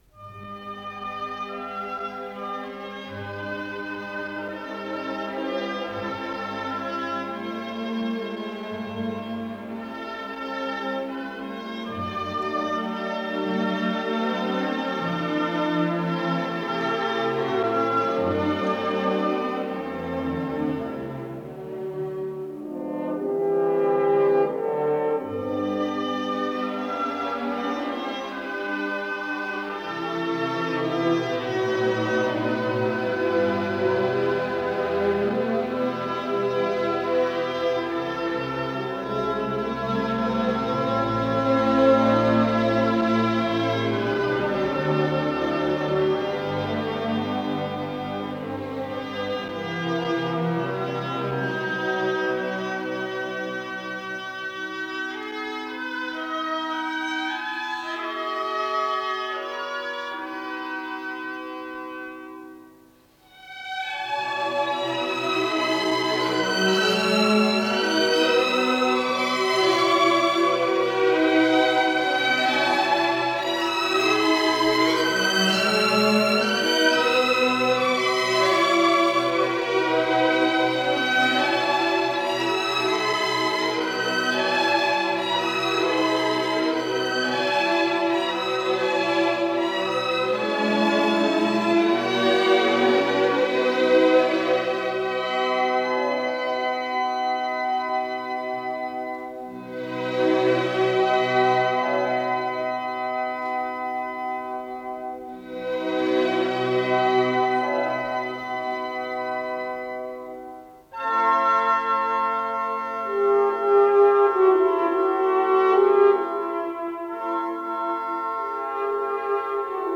ПКС-07521 — Симфония
Исполнитель: Государственный симфонический оркестр СССР
си минор